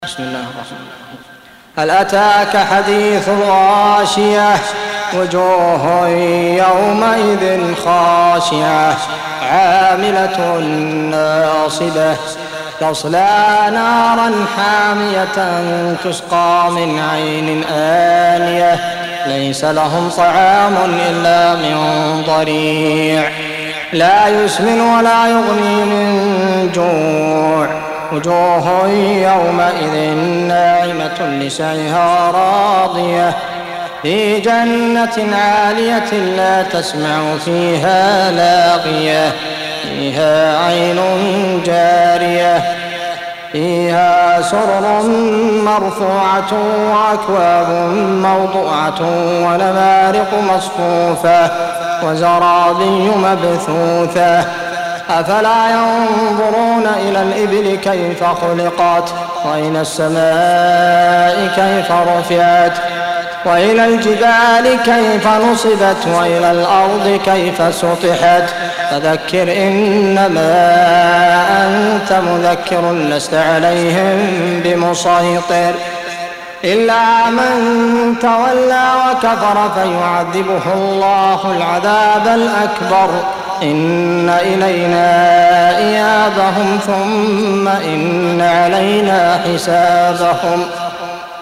88. Surah Al-Gh�shiyah سورة الغاشية Audio Quran Tarteel Recitation
حفص عن عاصم Hafs for Assem